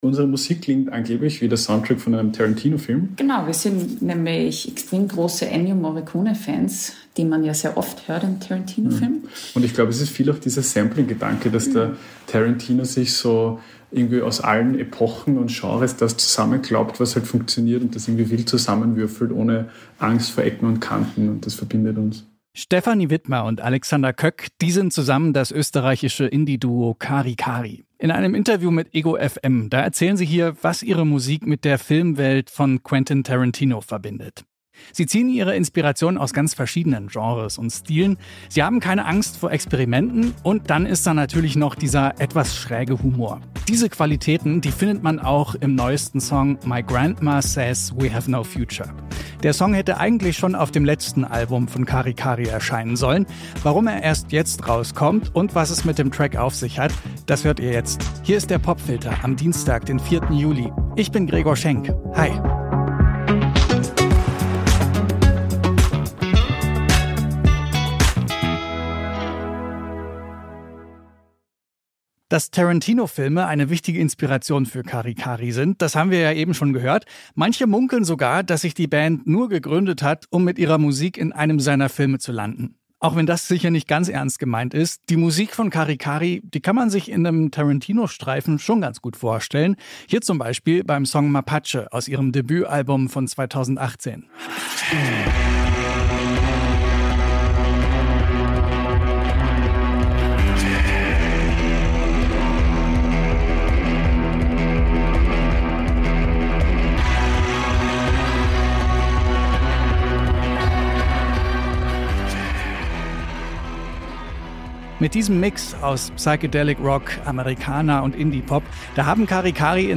Ernste Message, fröhlicher Sound: Der Song „My Grandma Says We Have No Future“ hätte eigentlich die Lead-Single zum zweiten Cari Cari-Album „Welcome To Kookoo Island“ sein sollen. Nun erscheint er auf einer limitierten Deluxeversion.